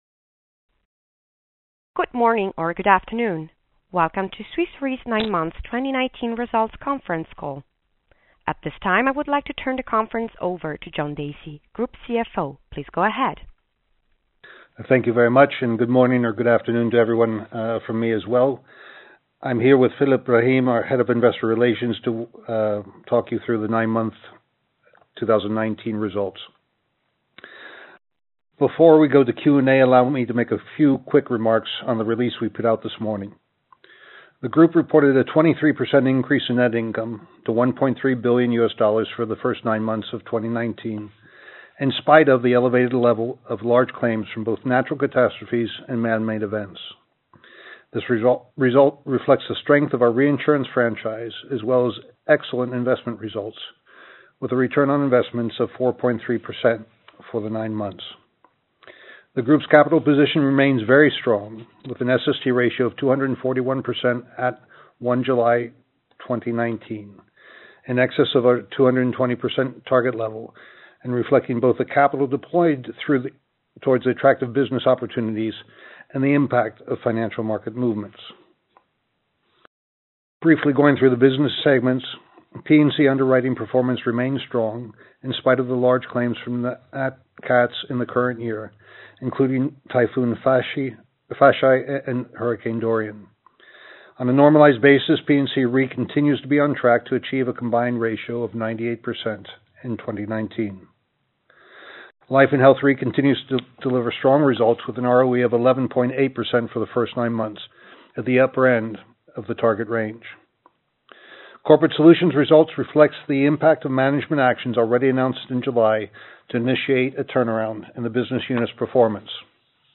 Nine Months 2019 Key Financial Data, Conference Call | Swiss Re
9m-2019-call-recording.mp3